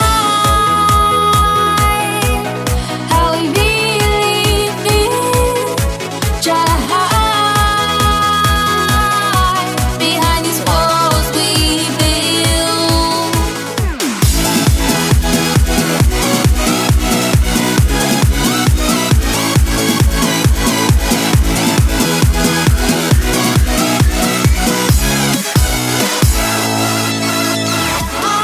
Genere: dance, club, edm, remix